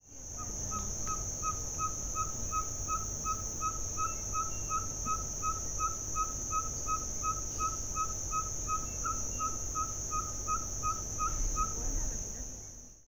Ferruginous Pygmy Owl (Glaucidium brasilianum)
Life Stage: Adult
Location or protected area: Parque Nacional Río Pilcomayo
Condition: Wild
Certainty: Observed, Recorded vocal
Gaucidium-brasilianum.mp3